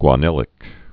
(gwä-nĭlĭk)